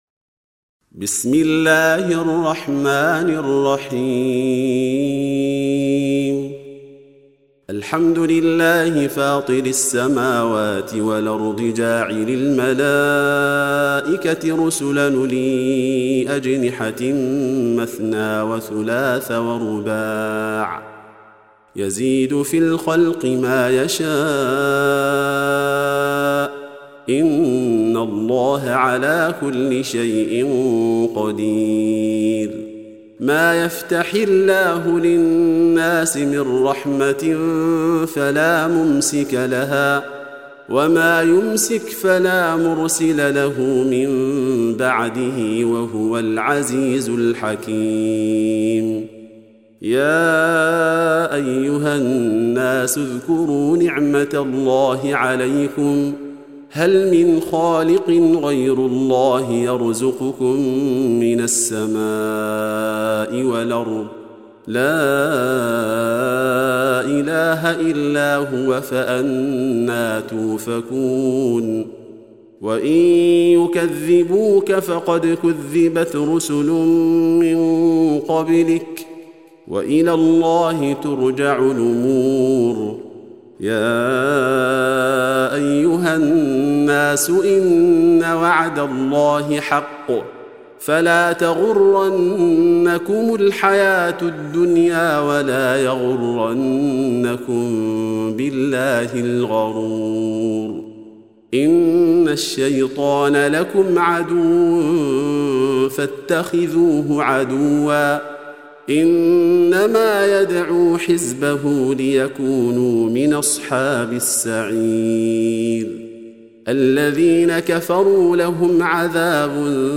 35. Surah F�tir or Al�Mal�'ikah سورة فاطر Audio Quran Tarteel Recitation
حفص عن عاصم Hafs for Assem